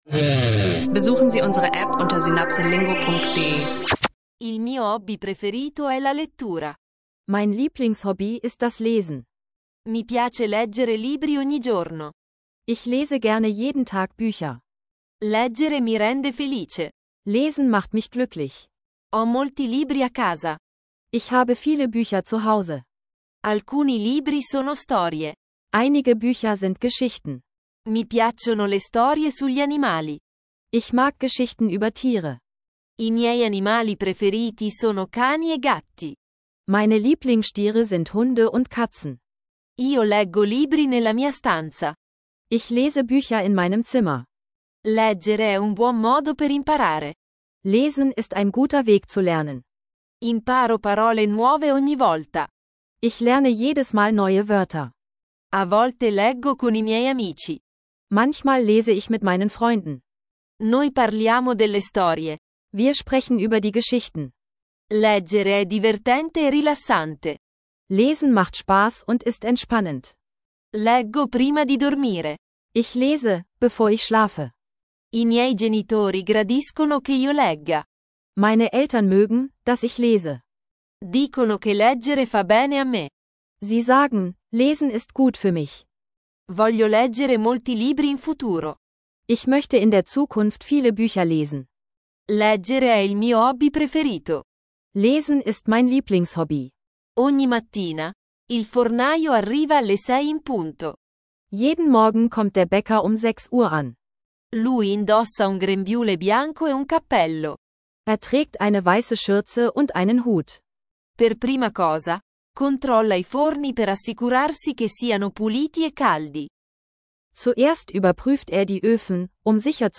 Tauche ein in vielseitige Italienisch-Lerninhalte wie Italienisch lernen online und Italienisch lernen Podcast mit kurzen Dialogen über Lesen, Bäckerei, Lokalpolitik, urbanen Verkehr und Gesundheit – ideal für Anfänger und Fortgeschrittene.